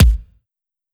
check-on.wav